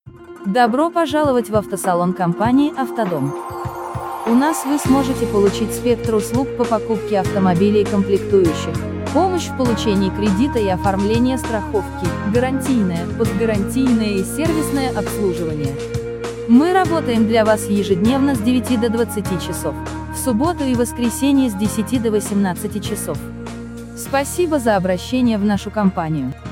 Озвучивание текста голосом.
Это может быть голосовое приветствие, голосовая почта, запись автоответчика и тому подобное. Возможно, при желании, добавление фоновой музыки.